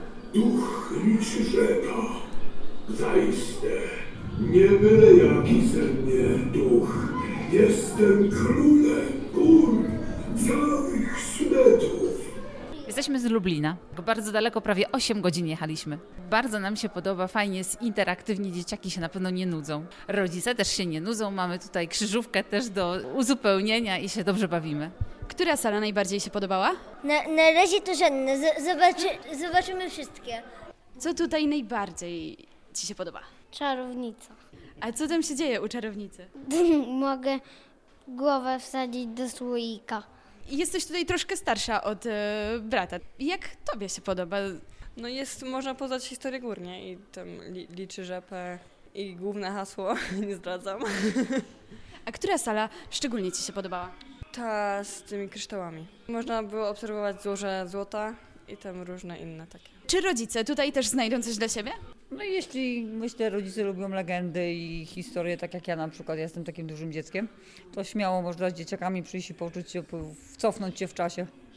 Zwiedzający poznają historię Liczyrzepy, Ducha Gór Orlickich czy Leśnego Luda. W rolę Liczyrzepy wcielił się aktor Robert Gonera, którego głos towarzyszy jednej z ekspozycji. O wrażeniach ze zwiedzania mówią turyści, których spotkaliśmy w muzeum.